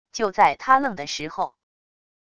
就在他愣的时候wav音频生成系统WAV Audio Player